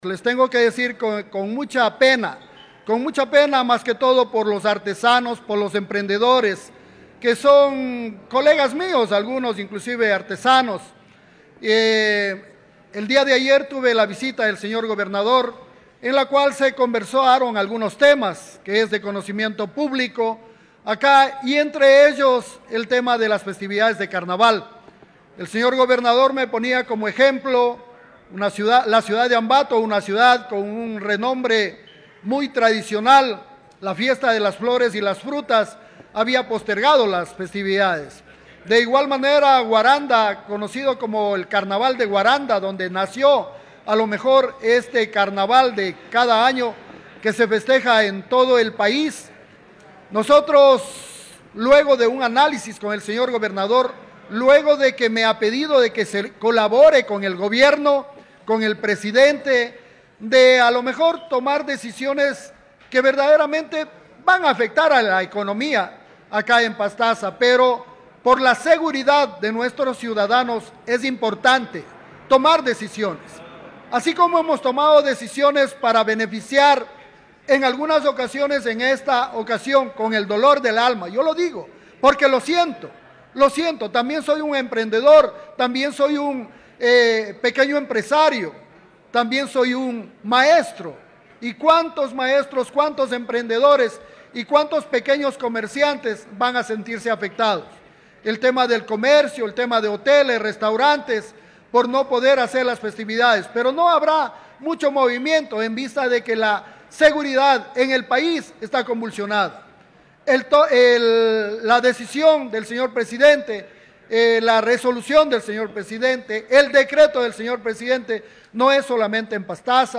Germán Flores, alcalde de Pastaza